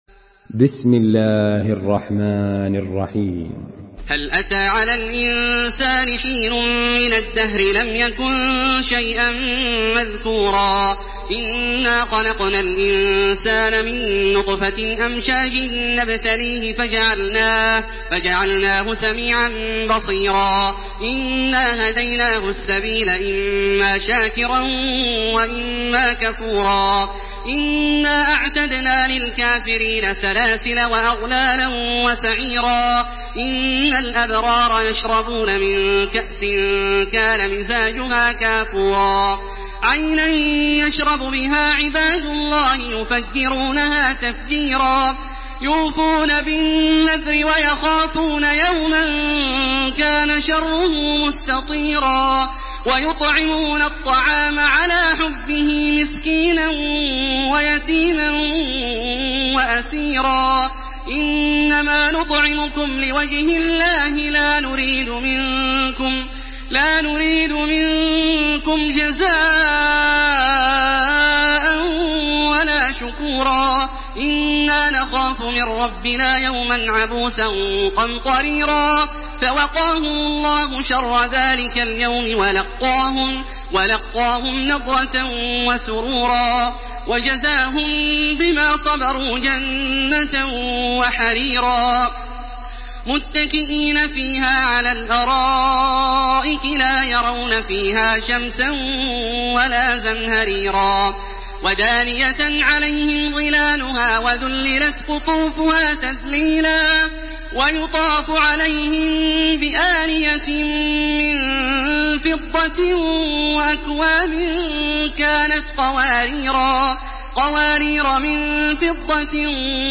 Surah আল-ইনসান MP3 in the Voice of Makkah Taraweeh 1429 in Hafs Narration
Murattal